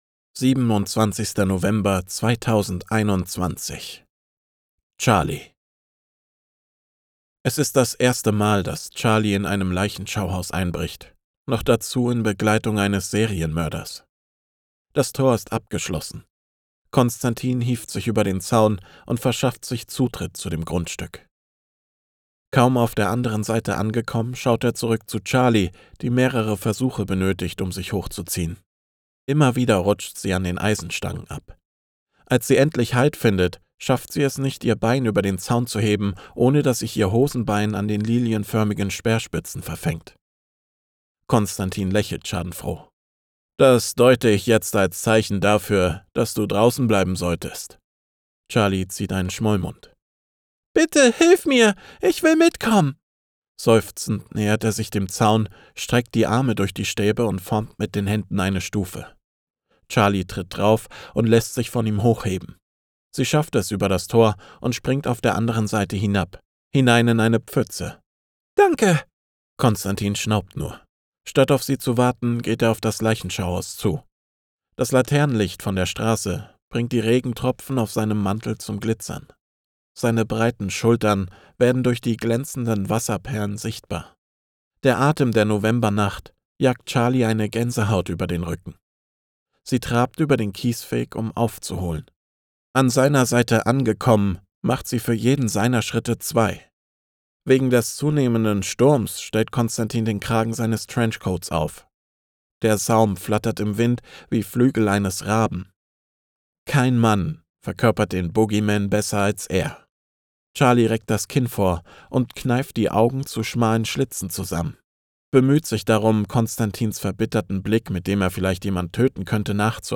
Willkommen in der Hörbuch Welt!